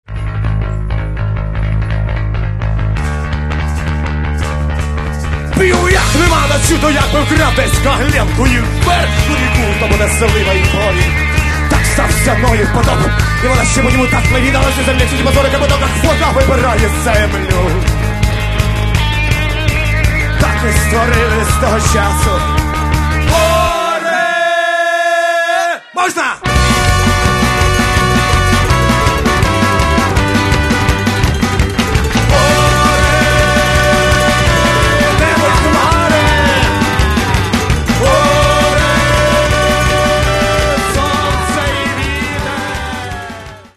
Еще немного громче и – до свидания!